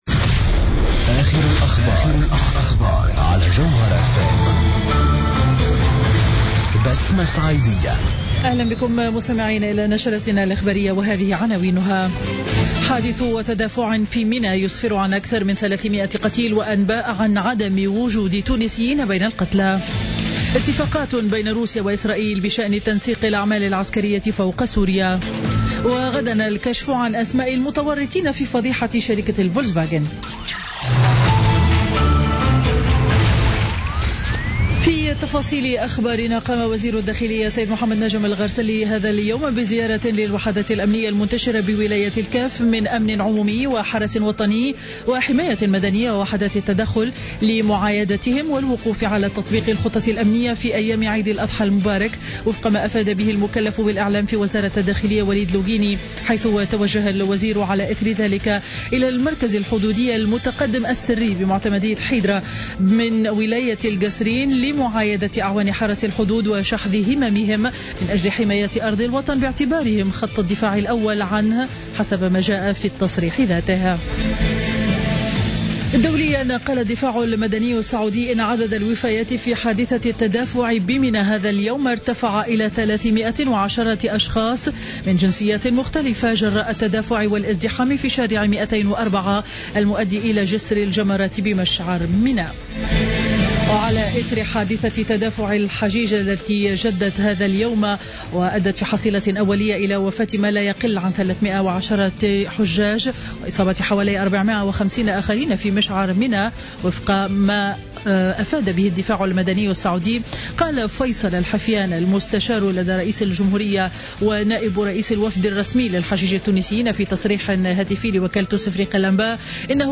نشرة أخبار منتصف النهار ليوم الخميس 24 سبتمبر 2015